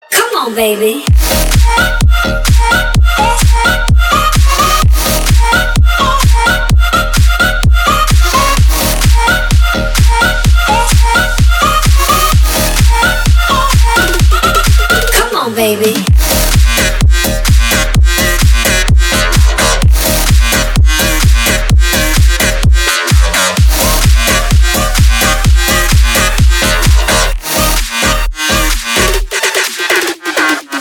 • Качество: 320, Stereo
future house
bounce
Стиль: House, Future House